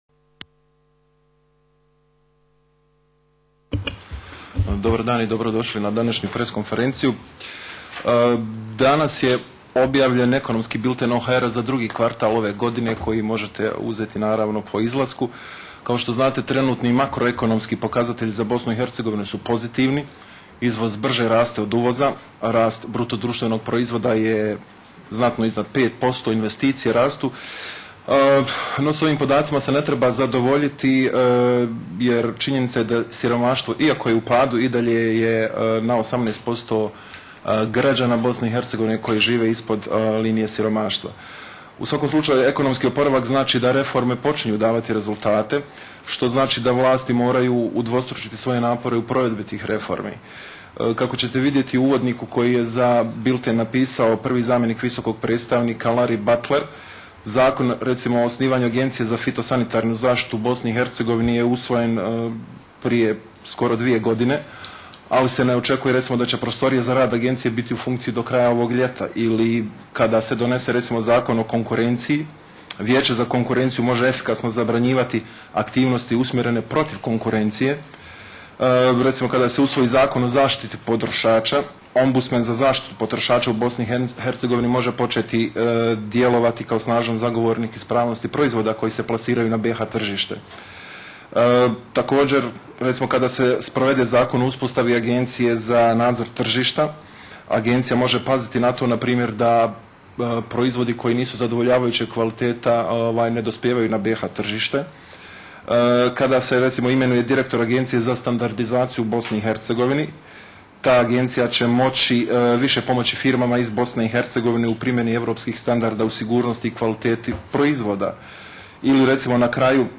Transcript of the International Agencies’ Joint Press Conference